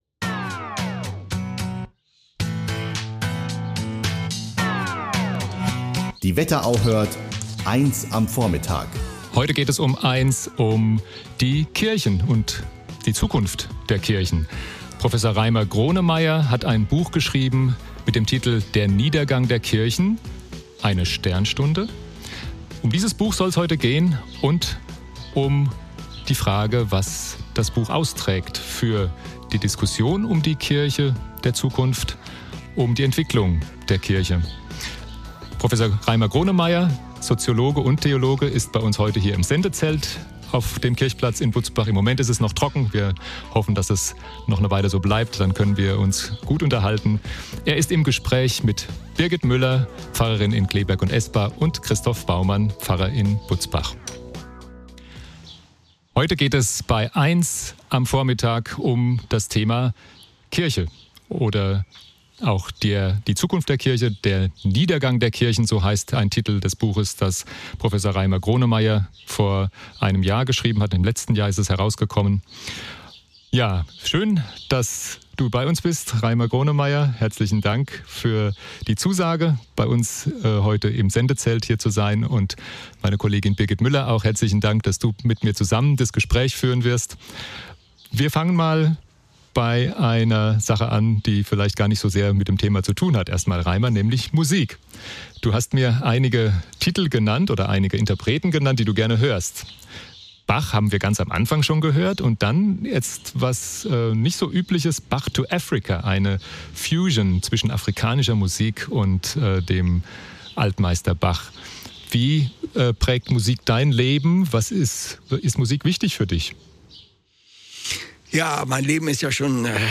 Mitschnitt der Sendung von Di, 6. Juli 2021. Der Soziologe und Theologe Reimer Gronemeyer hat kürzlich ein Buch geschrieben, in dem er sich mit der Zukunft der Kirchen beschäftigt.